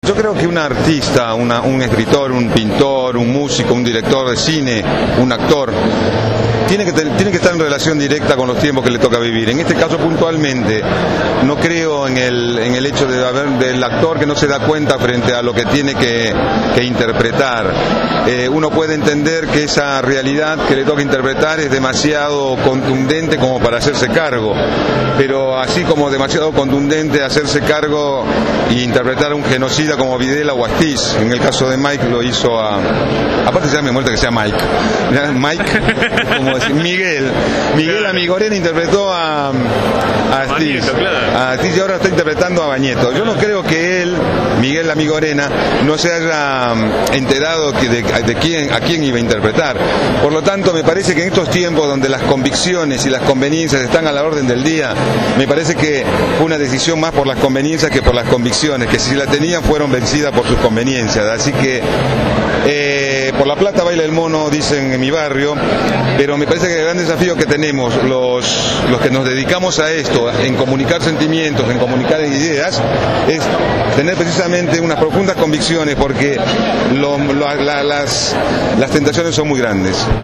El actor Juan Palomino participó de la convocatoria para conmemorar el sexto aniversario del rechazo al ALCA